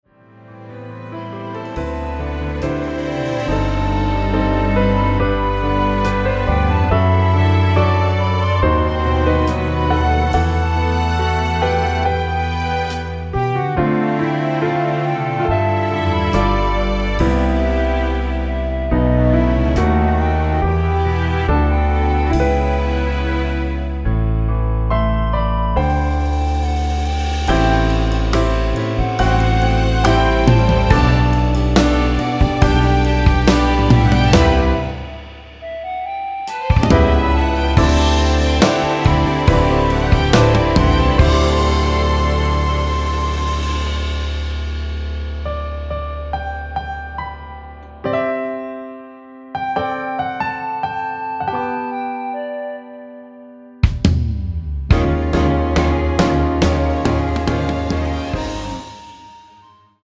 원키 멜로디 포함된(1절+후렴) 진행되게 편곡한 MR입니다.
1분 미리듣기는 “너라는 사람이” 부터 “이게 내 진심이야”까지 입니다.
Bb
앞부분30초, 뒷부분30초씩 편집해서 올려 드리고 있습니다.